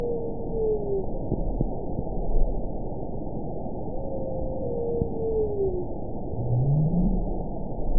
event 922092 date 12/26/24 time 09:55:08 GMT (11 months, 1 week ago) score 9.55 location TSS-AB04 detected by nrw target species NRW annotations +NRW Spectrogram: Frequency (kHz) vs. Time (s) audio not available .wav